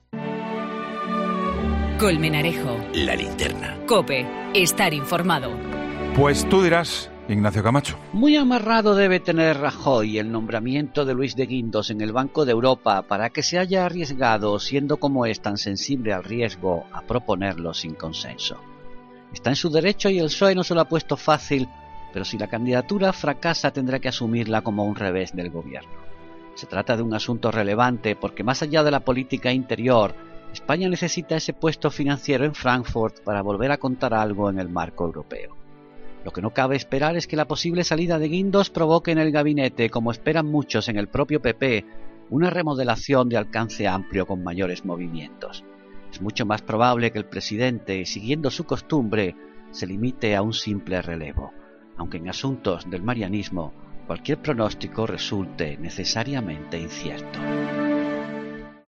El comentario de Ignacio Camacho en 'La Linterna', este miércoles sobre la candidatura de Luis De Guindos para la vicepresidencia del BCE.